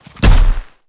Sfx_creature_snowstalker_run_os_03.ogg